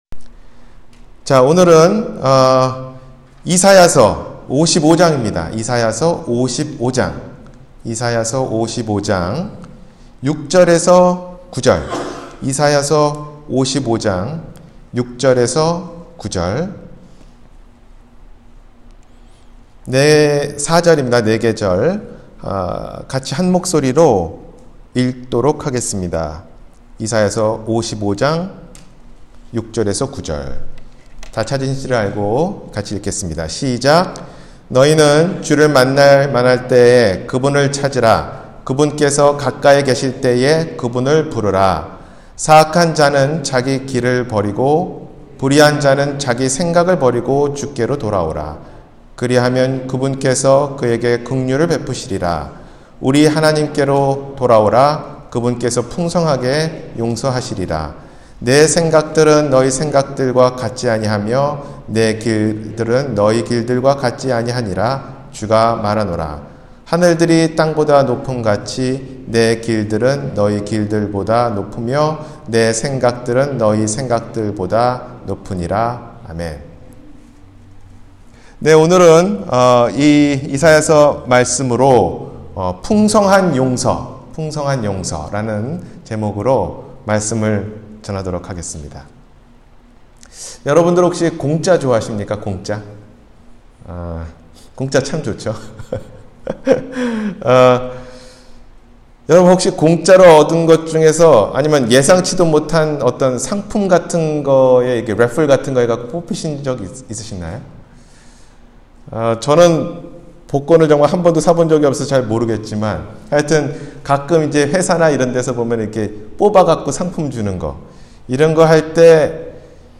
풍성한 용서 – 주일설교